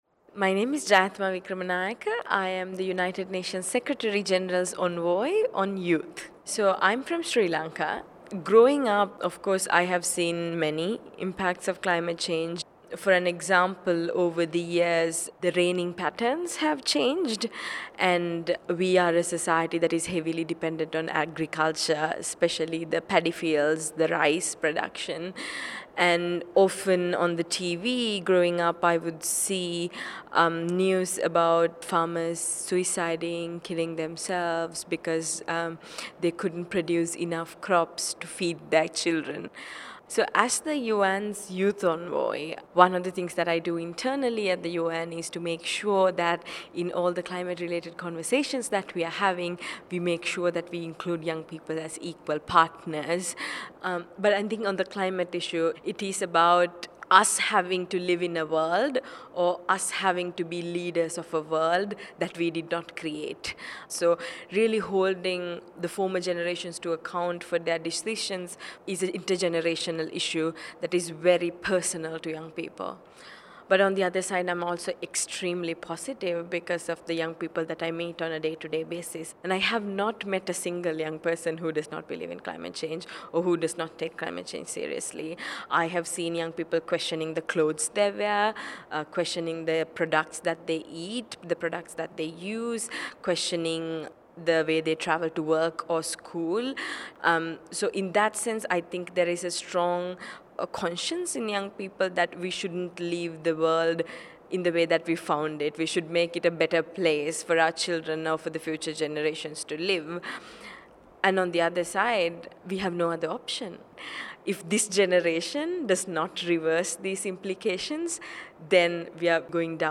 Here are some stories from the front lines of climate change that we gathered at the Global Climate Action Summit in San Francisco in mid-September (listen to each person talk by clicking the audio players below the images).
5. Jayathama Wickramanayake, UN secretary general’s envoy on youth